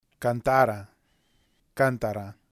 Vocales átonas y tónicas.